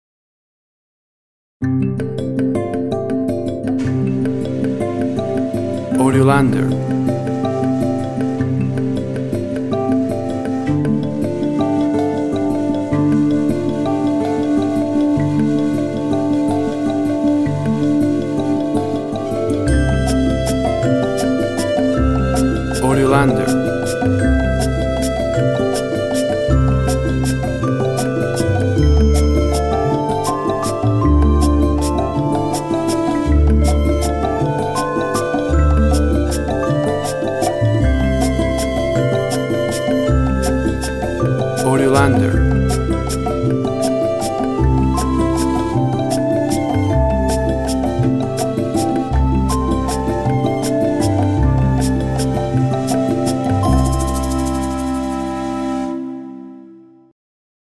Folk music and natural landscape.
Tempo (BPM) 185